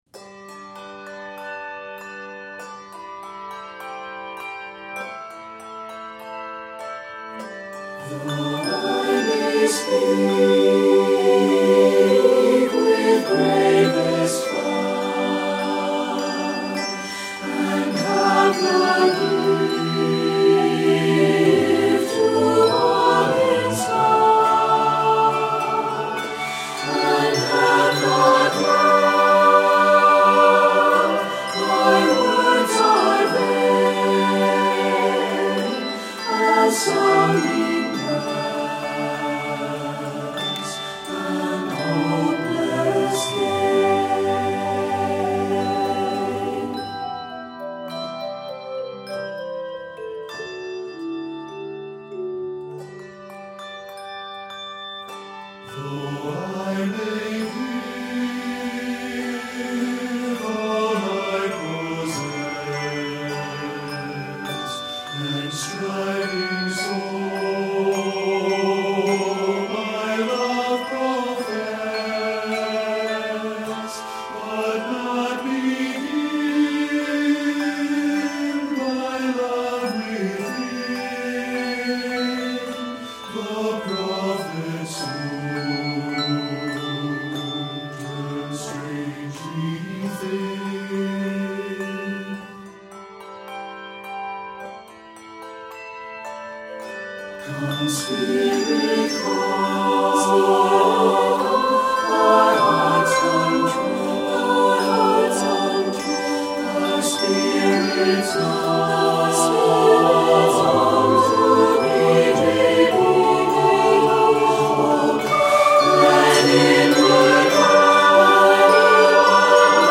the haunting tune is based on the traditional English melody